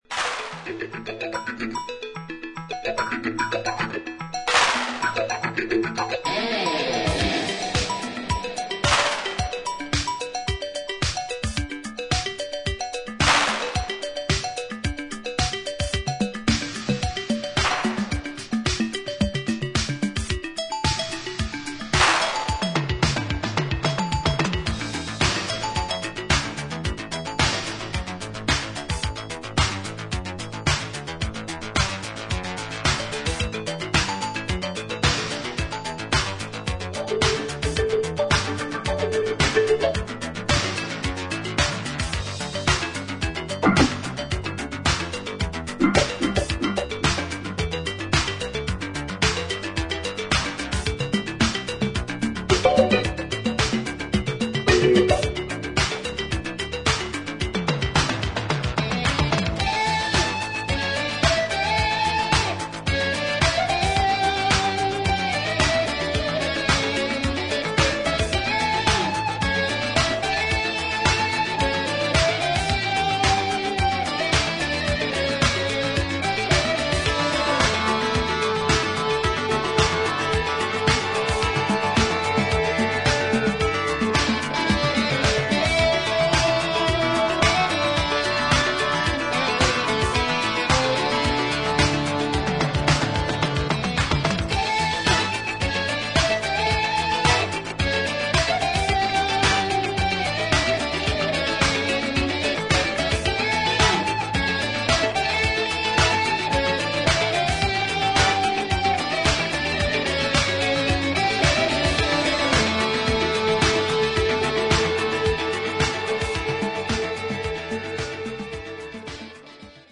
エレクトロニックでパーカッシブなスペイシー・フュージョン/ディスコ・ミックスが2ヴァージョン収録。